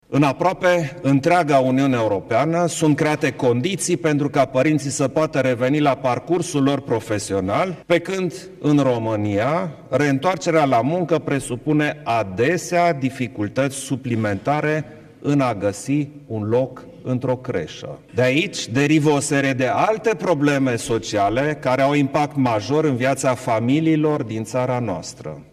Într-o conferință la Cotroceni despre proiectul România Educată, Klaus Iohannis a avertizat că, în țara noastră, nu se acordă deloc atenție educației antepreșcolare, iar acest lucru ar trebui să se schimbe.